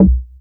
GIGA C4.wav